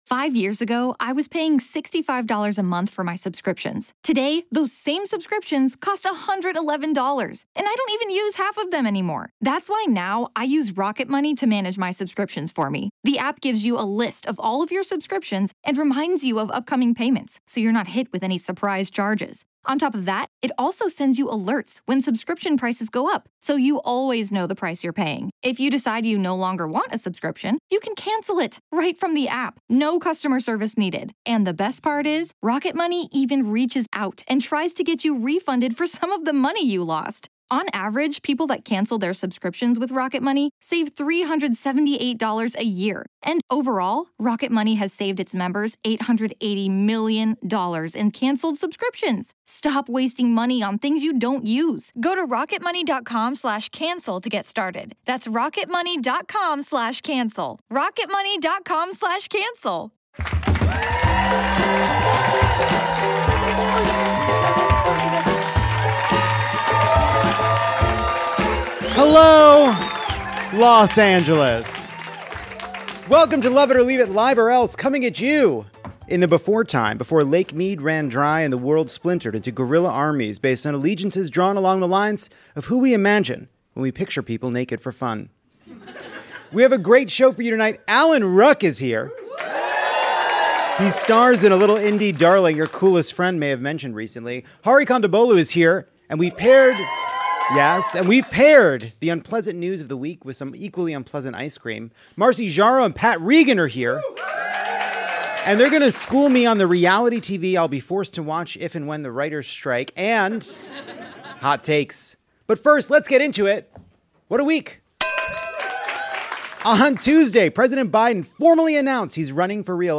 Lovett or Leave It is back at Dynasty Typewriter to give Tucker Carlson the sendoff he deserves: one he has to share with Don Lemon.